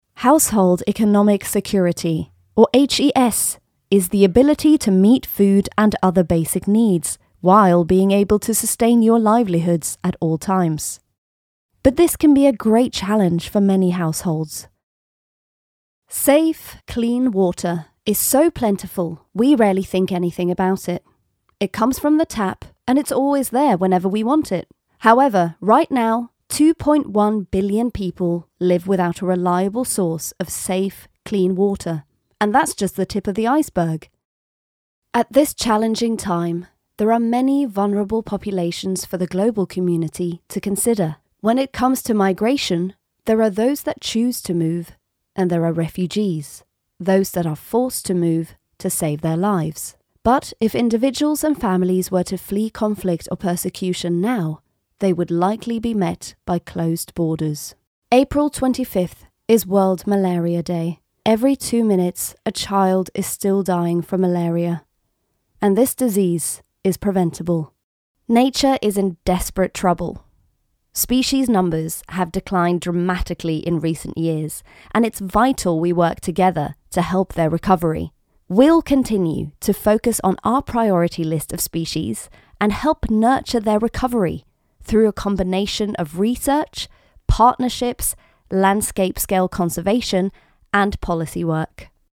Norwegian - Female
Charity Showreel
Commercial, Light, Friendly, Showreel, Smooth